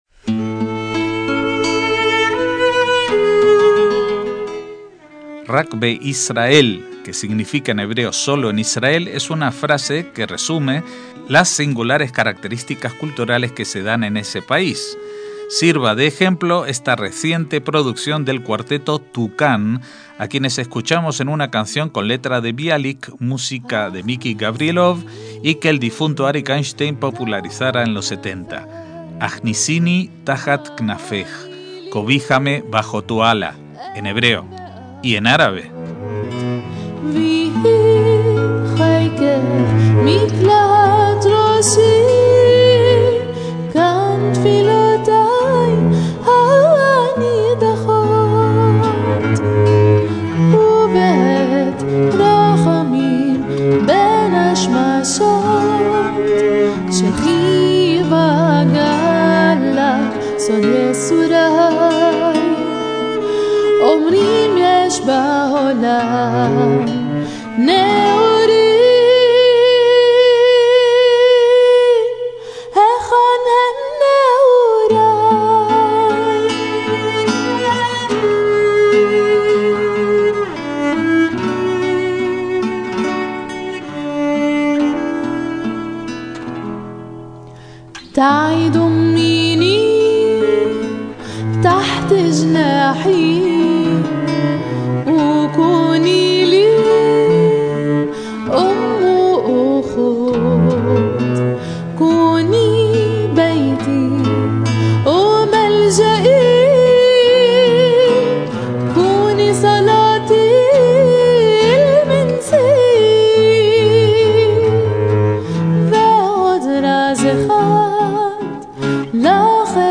la cantante árabe clásica